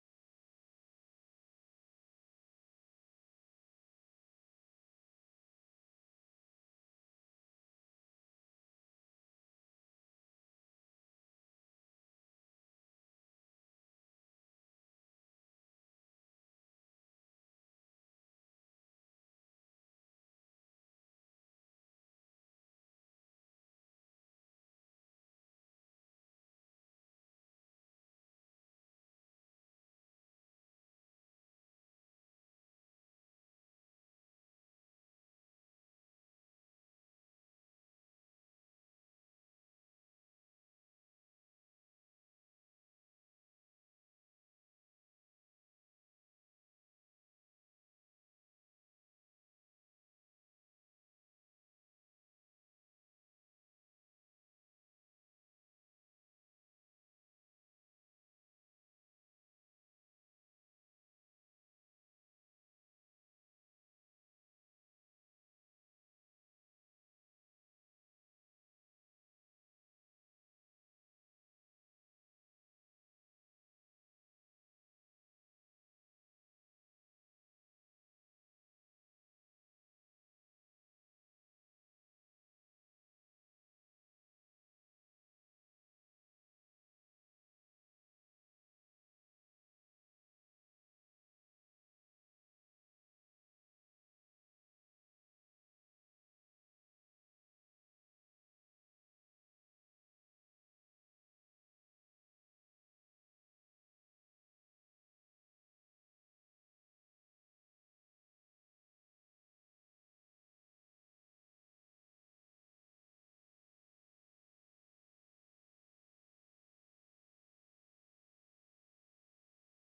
Passage: Acts 20:17-24, 28-32 Service Type: Sunday AM